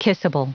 Prononciation du mot kissable en anglais (fichier audio)
Prononciation du mot : kissable